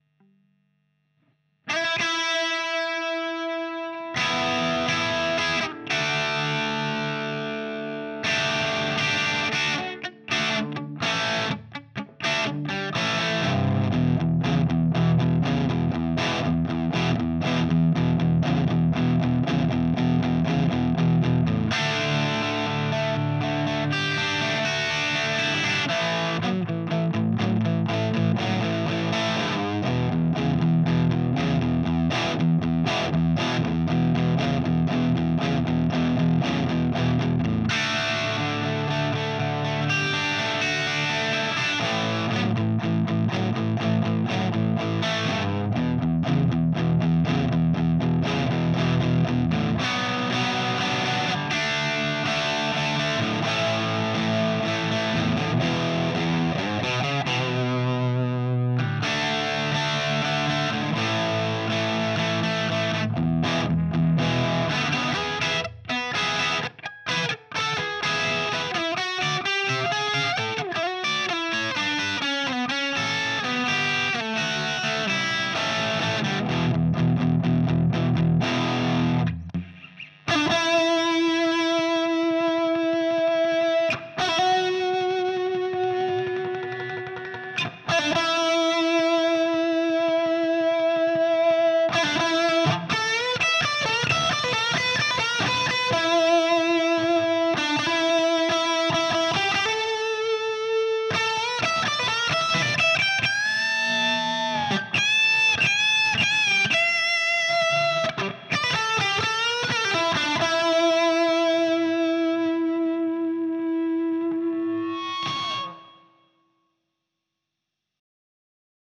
Ok, toanthreaders - Here's a quick JVM tone for your perusal. The first part of the clip is Crunch channel, orange mode.
Lead solo: OD2 channel, orange mode. Vol. 7, Bass 4, Middle 4.5, Treble 2.5, Gain 1.5. Master vol 6.5, Resonance 3, Presence 4.5. SM57 on the grille (not very carefully placed! ), Standard 1960A cab.